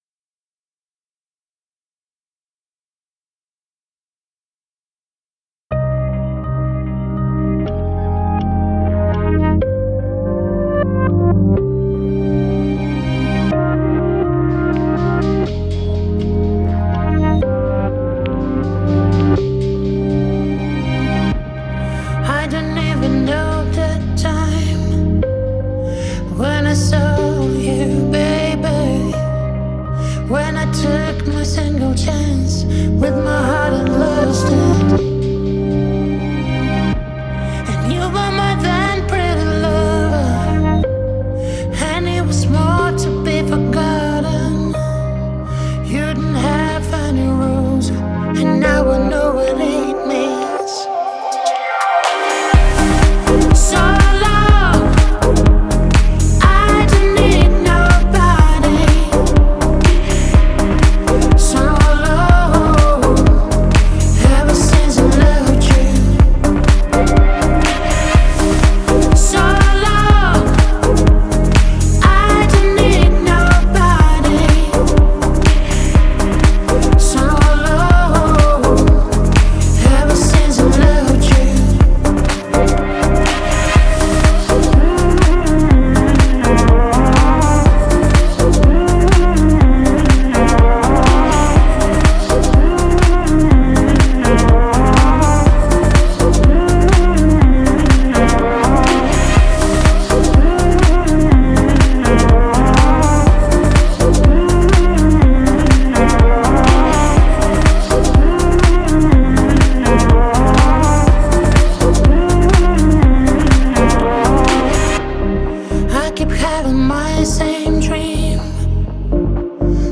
Ммм...так долго.. и пронзительно...